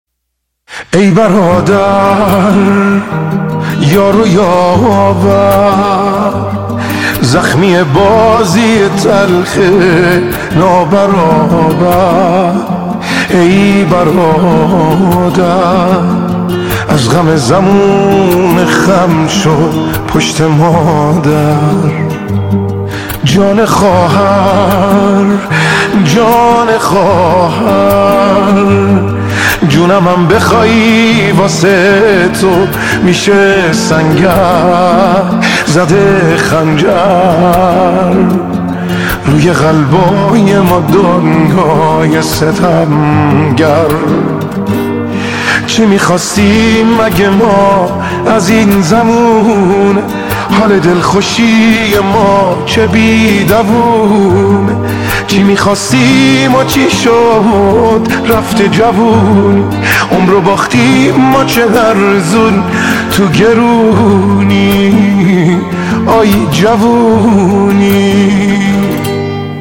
“آهنگ دلی”